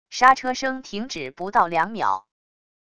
刹车声停止不到两秒wav音频